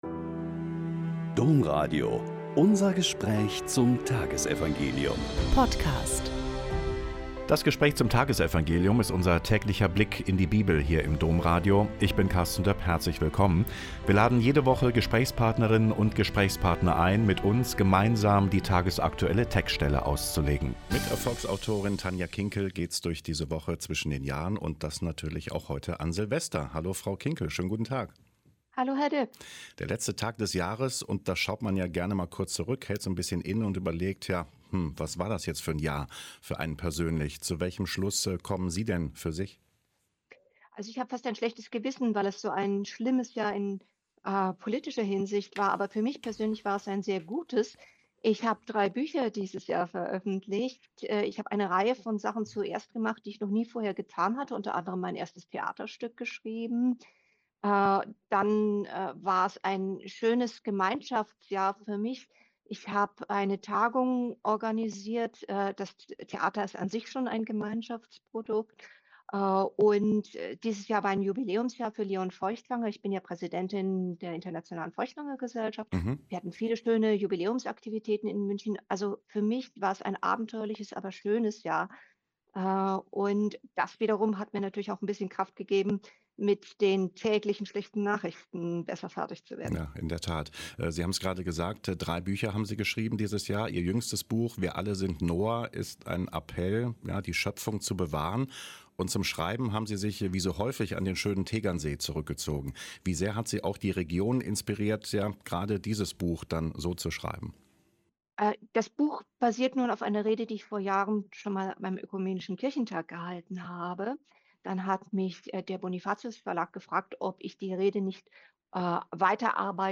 Joh 1,1-18 - Gespräch mit Dr. Tanja Kinkel